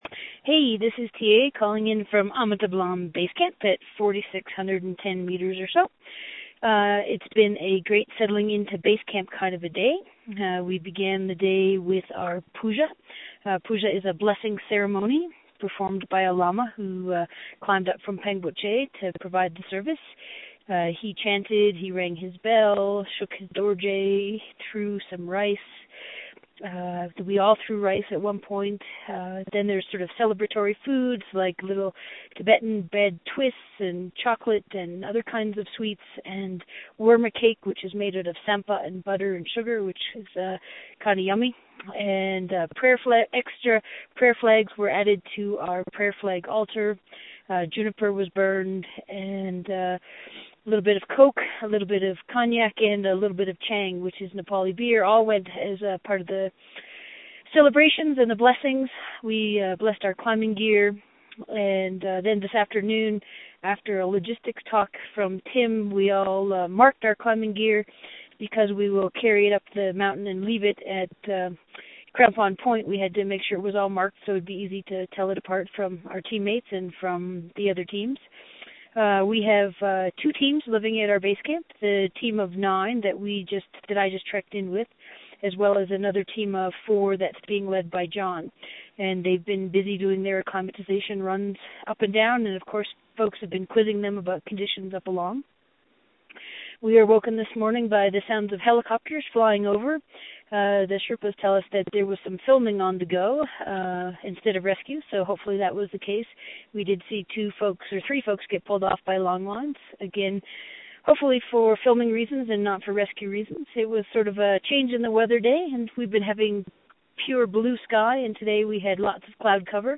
Audio Post, Puja at base camp